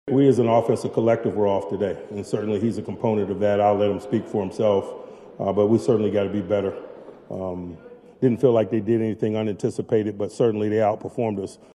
Steelers Head Coach Mike Tomlin said that Rodgers did not have his best stuff.